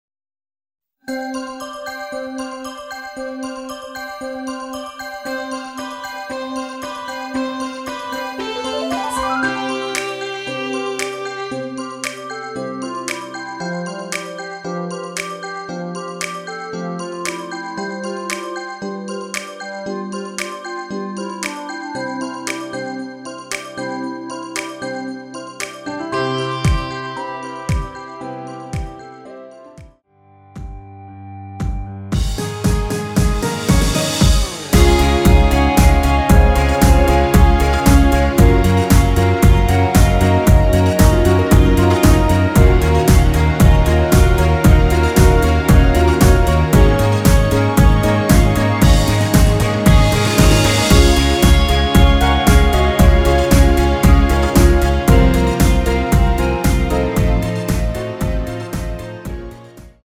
엔딩이 페이드 아웃이라 노래 부르기 좋게 엔딩 만들었습니다.
원키에서(-3)내린 멜로디 포함된 MR입니다.
노래방에서 노래를 부르실때 노래 부분에 가이드 멜로디가 따라 나와서
앞부분30초, 뒷부분30초씩 편집해서 올려 드리고 있습니다.